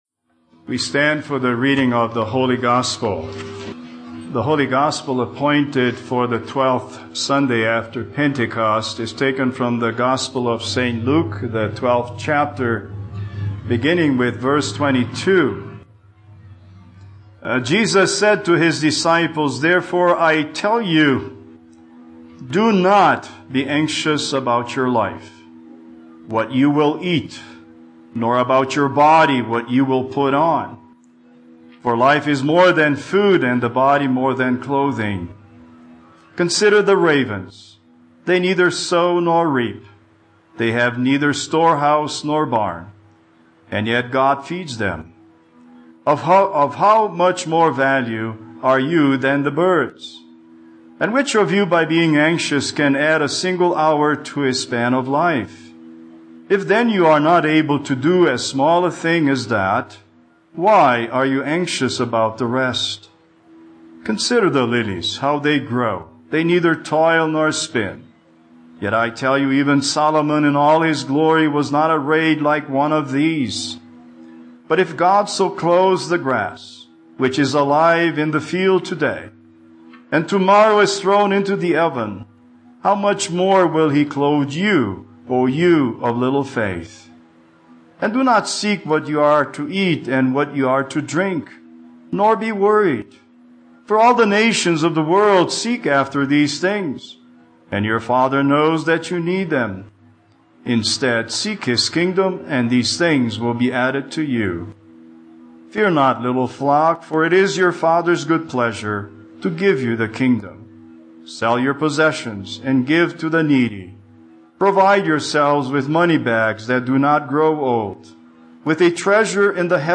Speaker: Vacancy Pastor